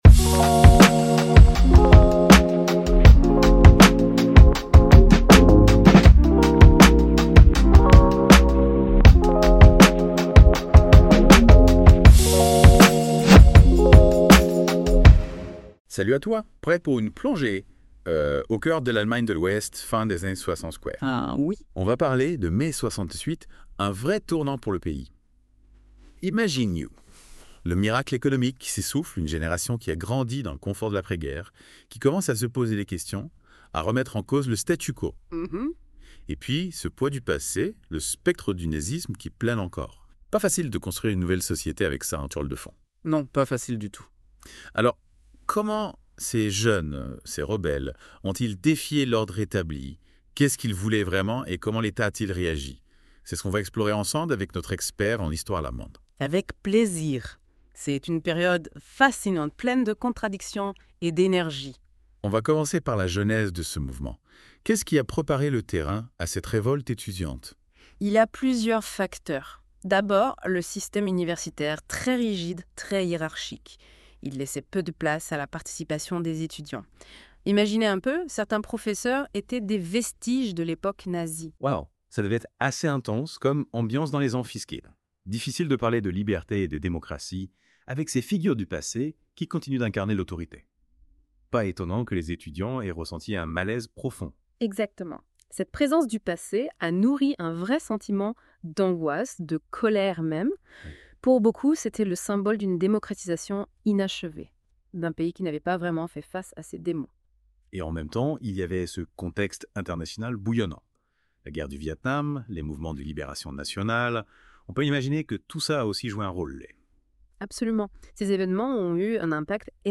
Allgemeine Einführung, einige Worte nicht richtig ausgesprochen oder amerikanisiert.